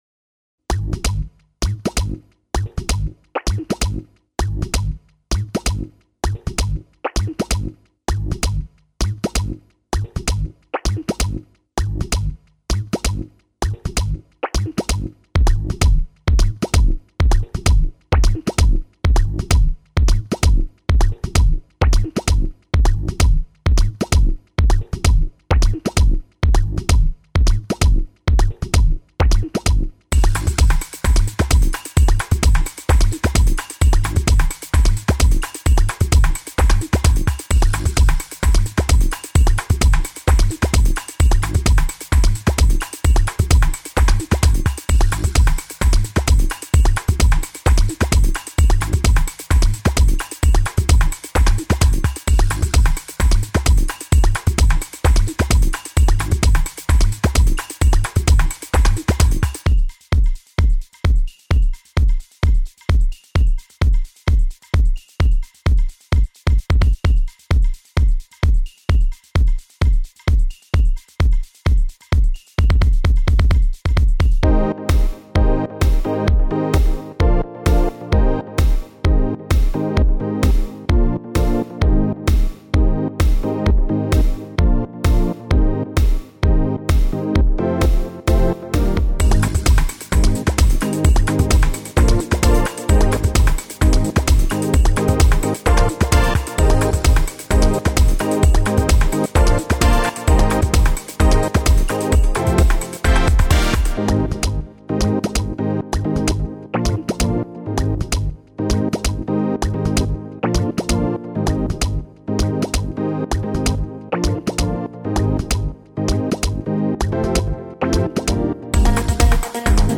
dance/electronic
Breaks & beats